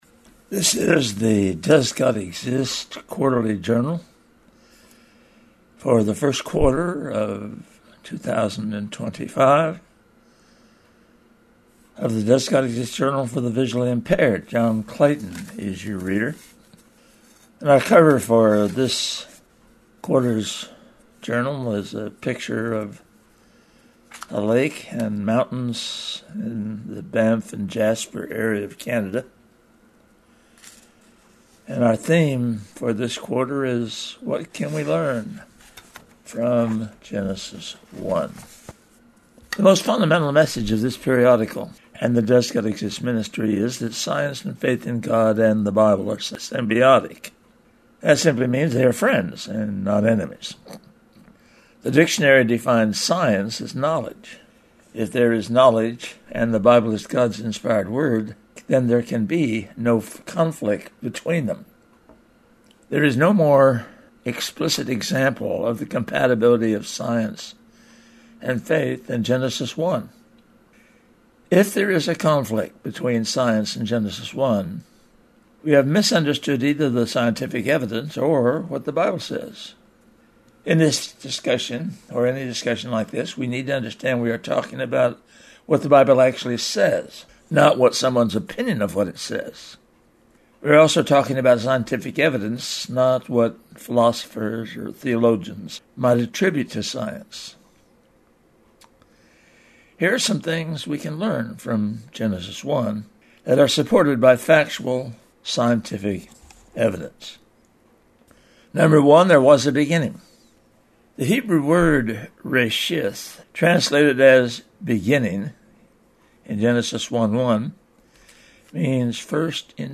For the visually impaired we are providing an audio version of our journal online.